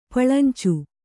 ♪ paḷancu